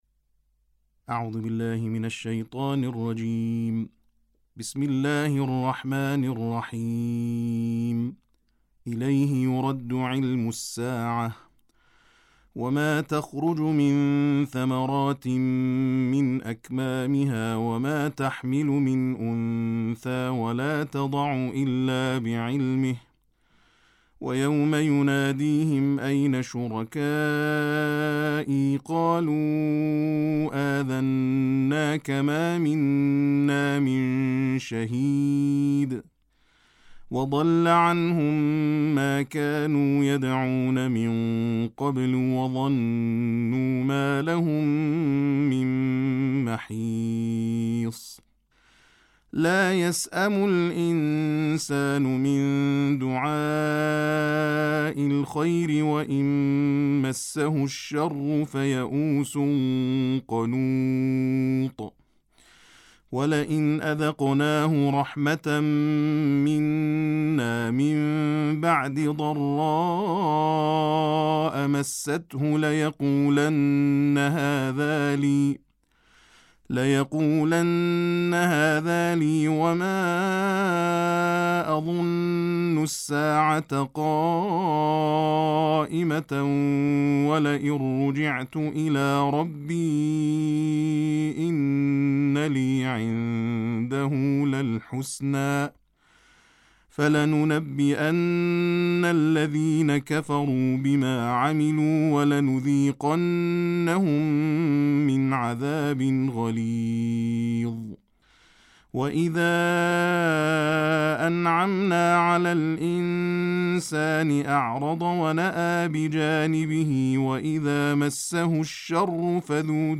تلاوت ترتیل جزء بیست‌وپنجم قرآن